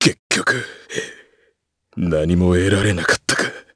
Chase-Vox_Dead_jp.wav